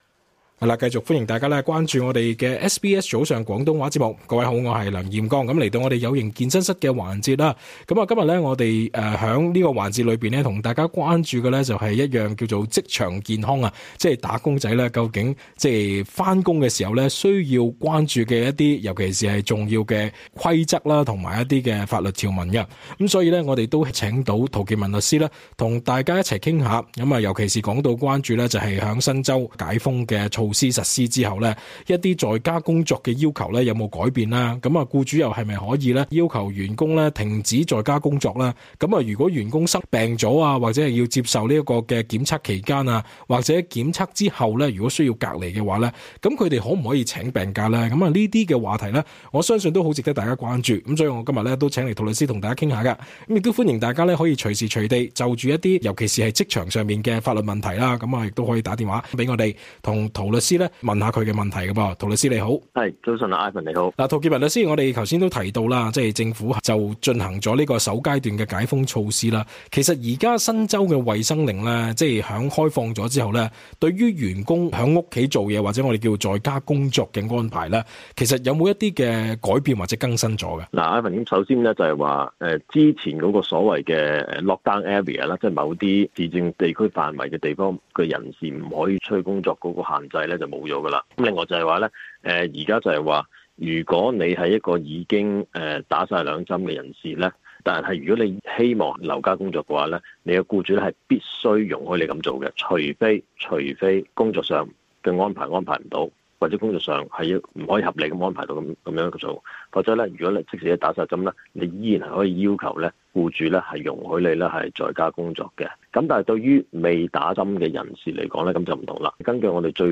talkback_what_new_right_do_employees_have_regarding_wfh_after_nsw_start_to_reopen.mp3